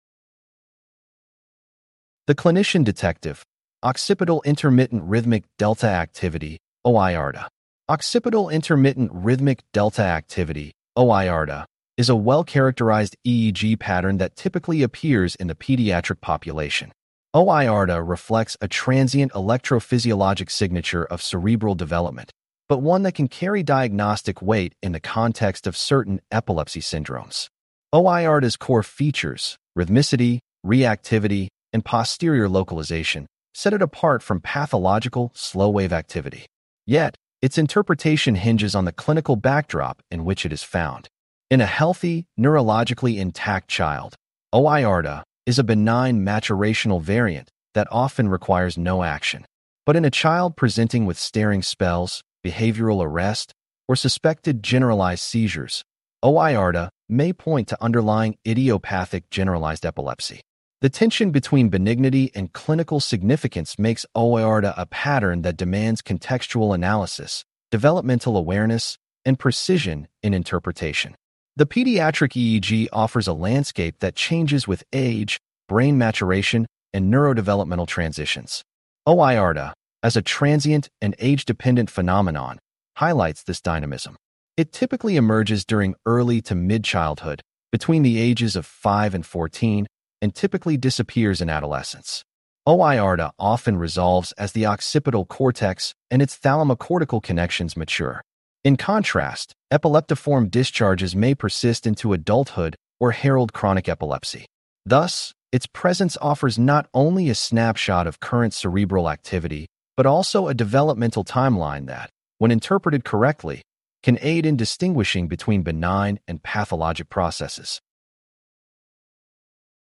CLICK TO HEAR THIS POST NARRATED OIRDA reflects a transient electrophysiologic signature of cerebral development, but one that can carry diagnostic weight in the context of certain epilepsy syndromes.